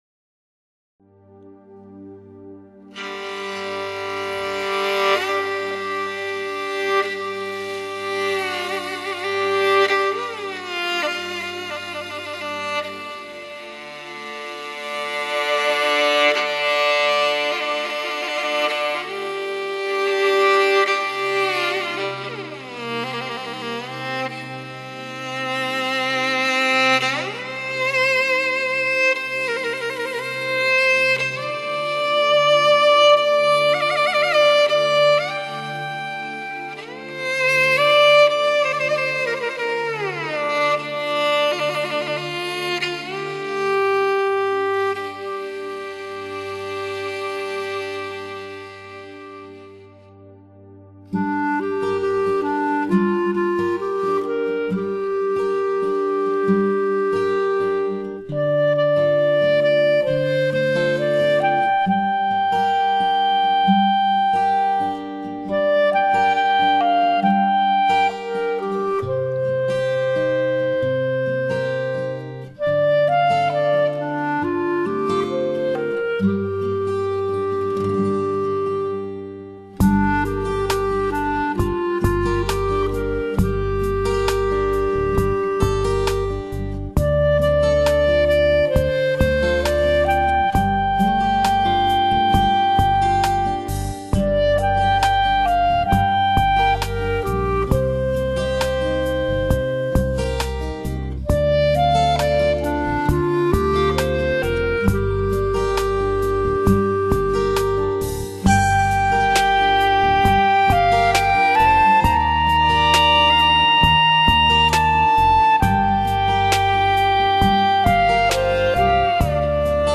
木琴 单簧管
技艺精湛，风格清新。
发烧级的音场，震撼每对挑剔的耳朵！